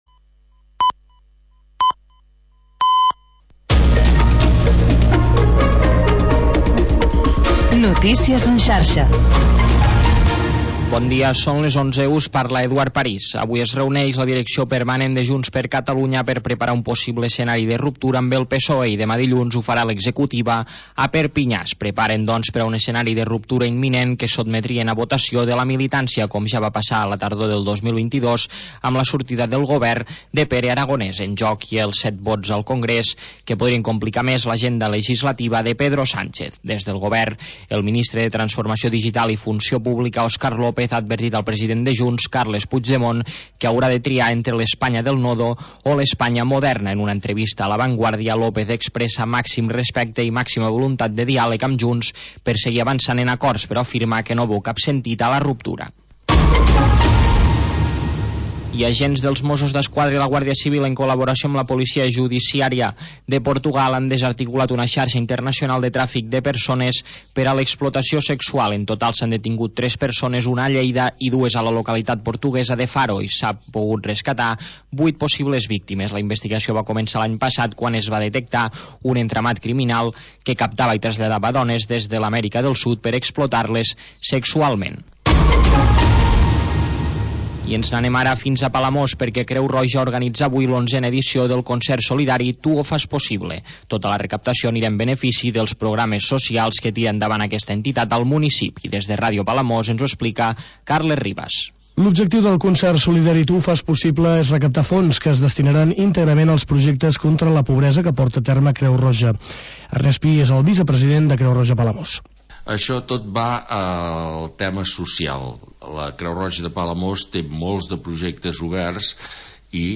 Havanera, cant de taverna i cançó marinera. 15 anys obrint una finestra al mar per deixar entrar els sons més mariners